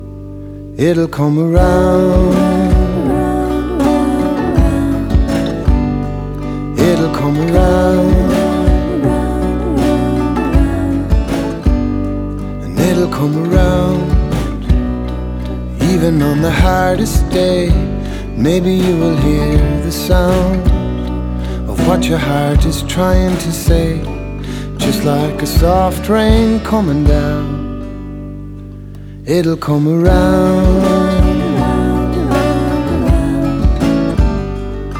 # Contemporary Folk